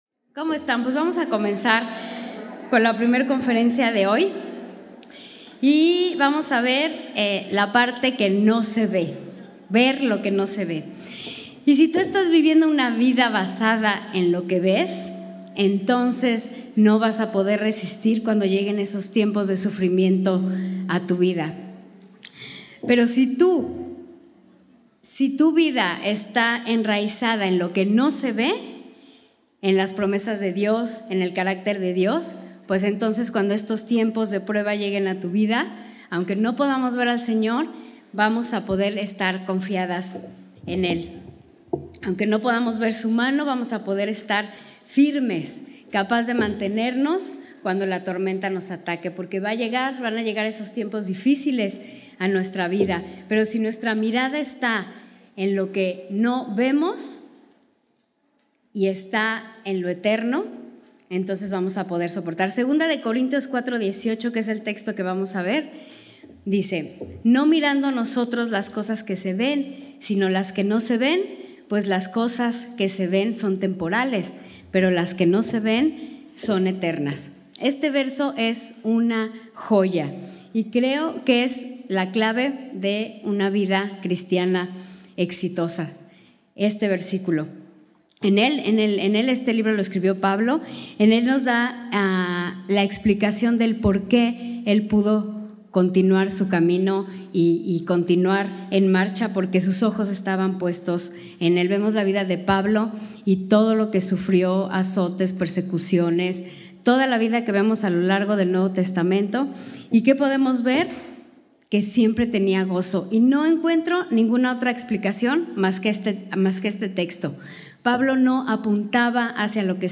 Retiro 2018